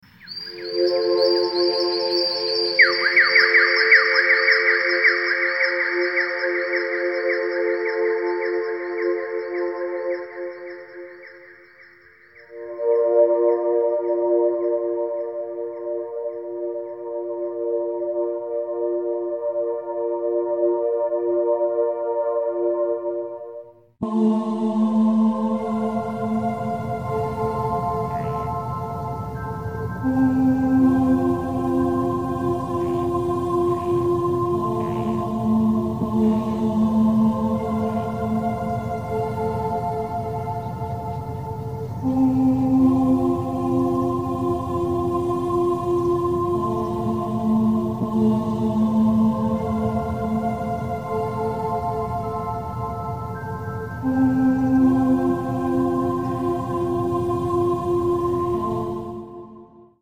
Meditation Sound